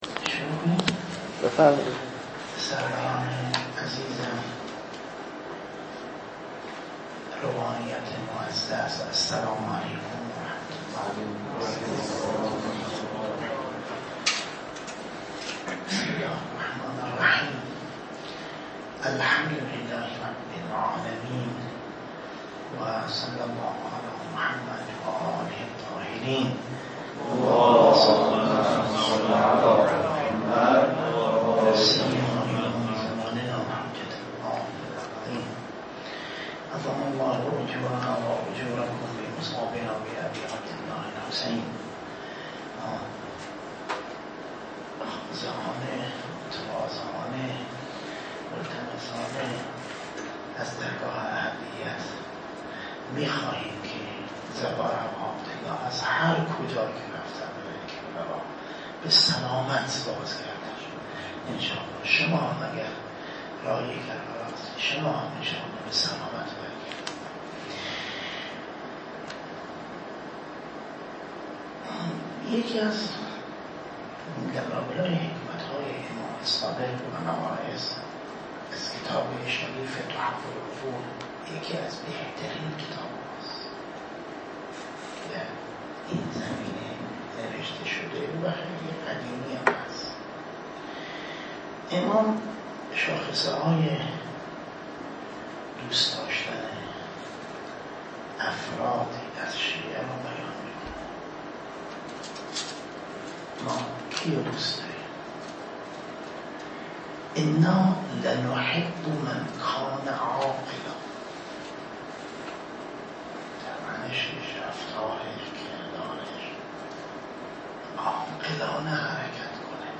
🔰مراسم اختتامیه دوره تابستانه مدرسه عالی امام حسین علیه السلام